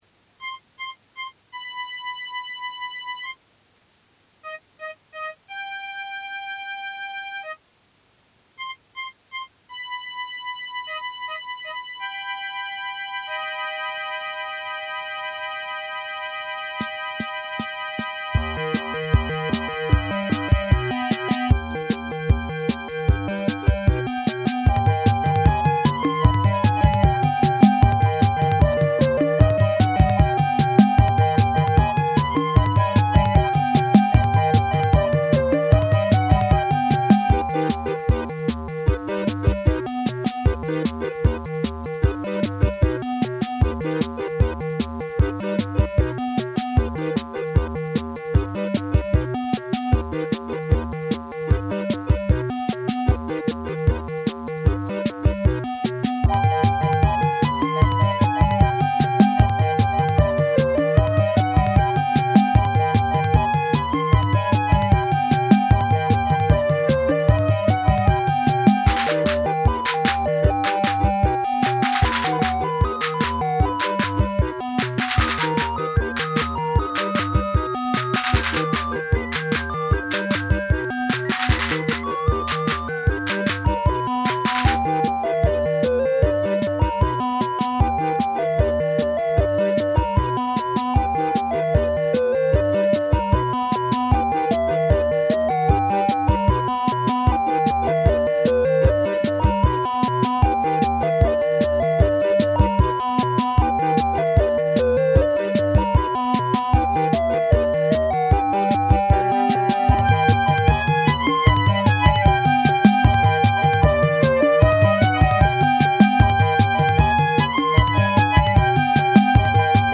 Le morceau, c’est “Wedding” mais dans une version instrumentale.
Appréciez le joli souffle: les masters étaient conservés sur des cassettes audio chrome.